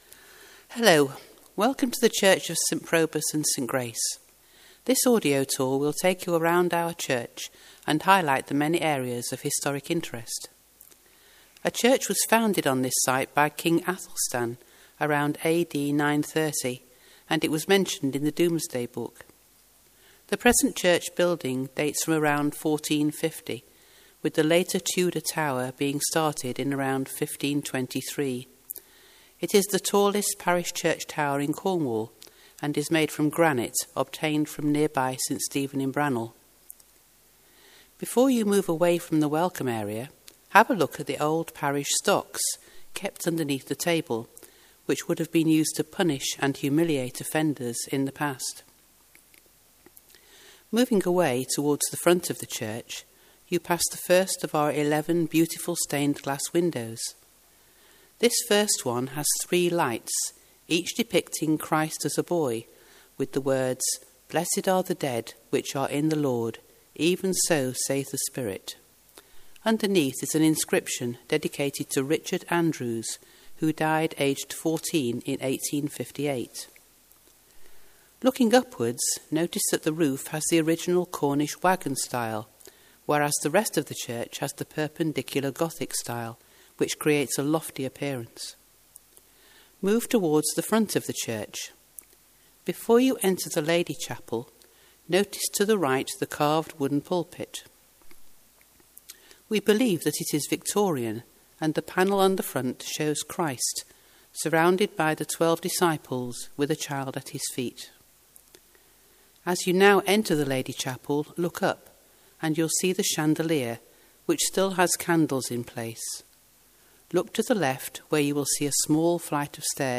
For an audio tour of St Probus and St Grace please click here